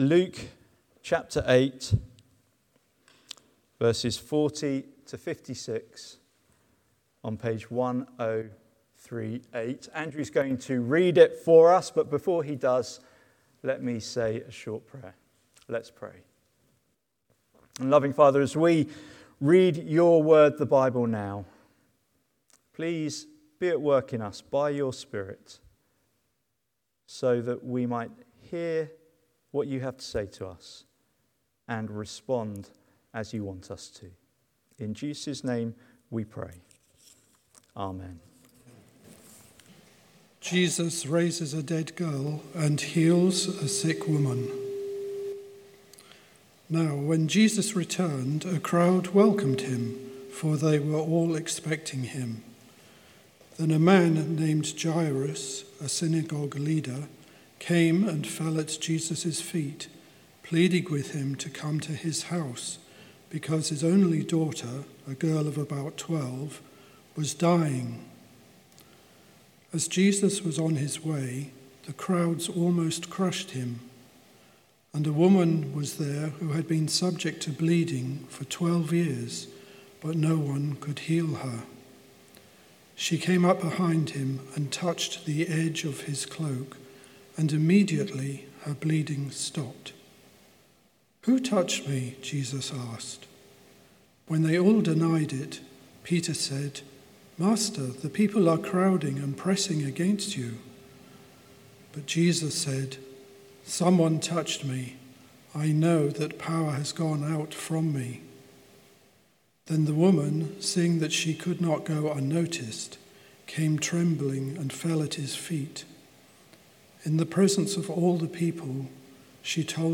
Sermon Transcript Study Questions